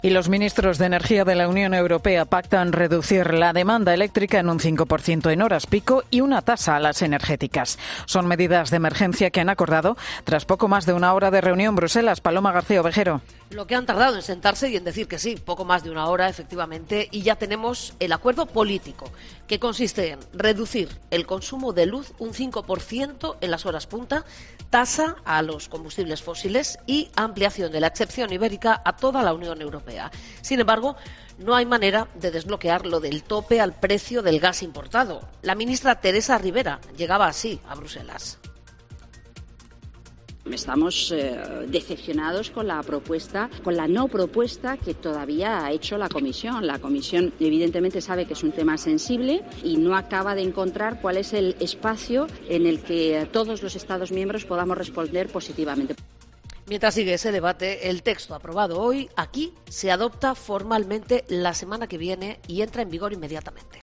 Crónica